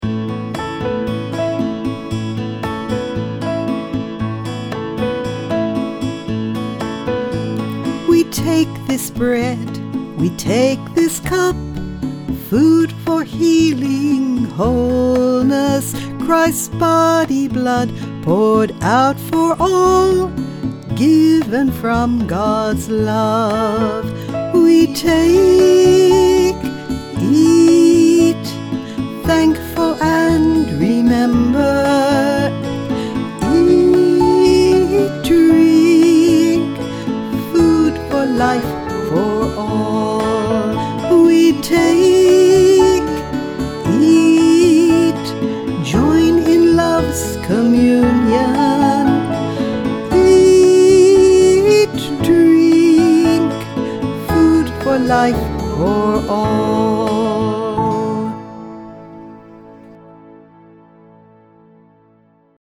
mp3 vocal